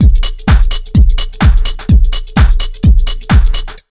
127BEAT4 1-L.wav